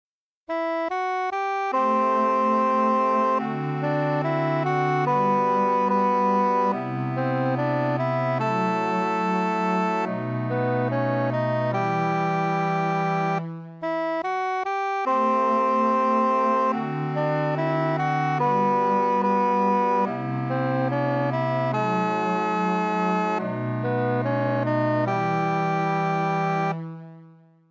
> and then a three voice jazz renderring